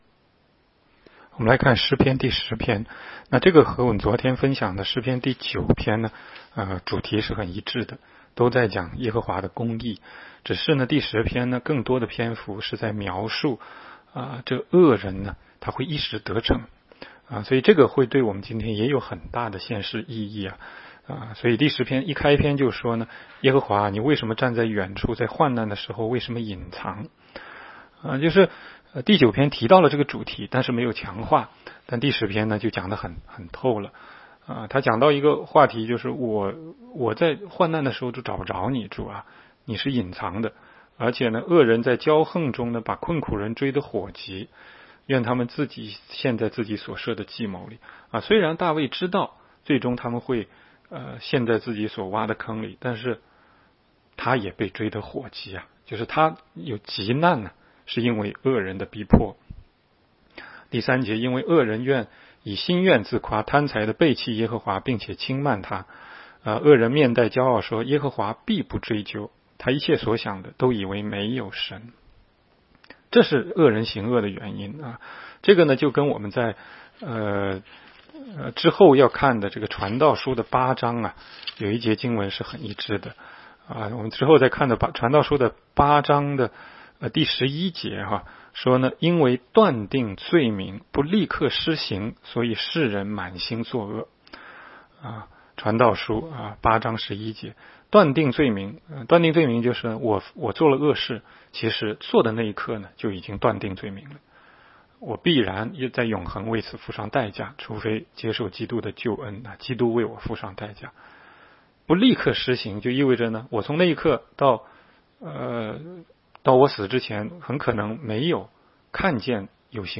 16街讲道录音 - 每日读经-《诗篇》10章